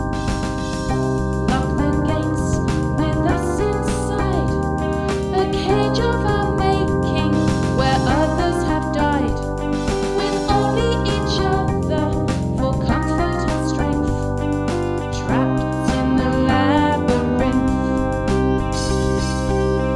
Music Samples